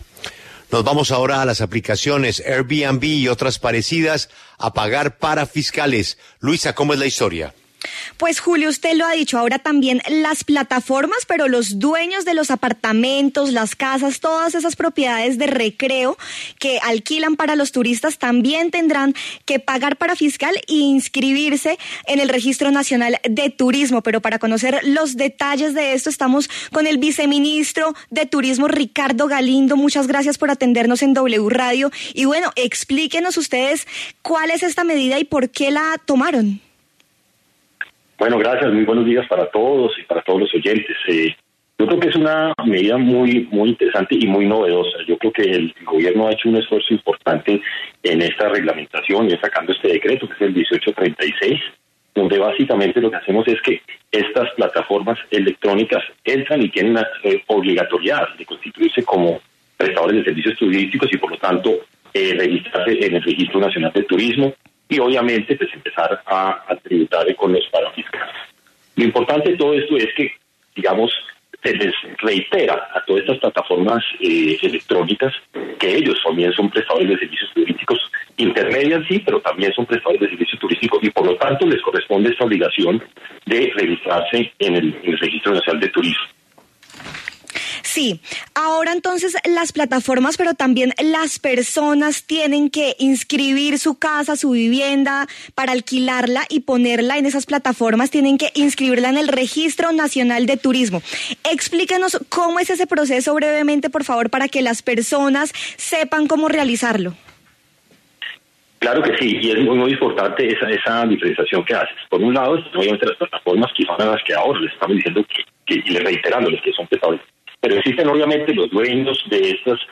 En diálogo con La W, el viceministro de Turismo, Ricardo Galindo, se refiere a la inscripción en el Registro Nacional de Turismo para quienes ofrezcan sus propiedades en plataformas digitales.